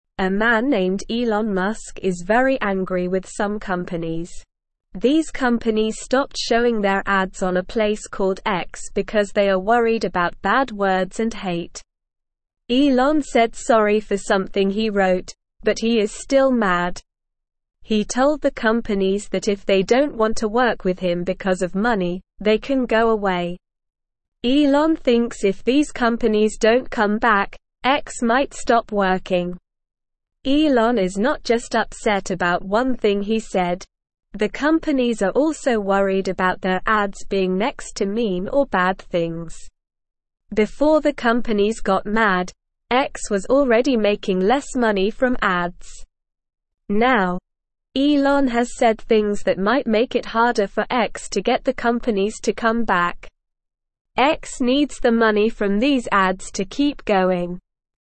Slow
English-Newsroom-Beginner-SLOW-Reading-Elon-Musk-Angry-as-Companies-Stop-Ads-on-X.mp3